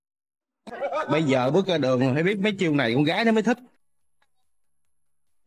Thể loại: Câu nói Viral Việt Nam
Description: Đây là âm thanh nền lý tưởng cho các video edit vui nhộn, clip lồng tiếng ngắn, vlog đời thường hoặc các pha tấu hài. Một câu nói viral với chất giọng duyên dáng, tạo điểm nhấn cho mọi khung hình.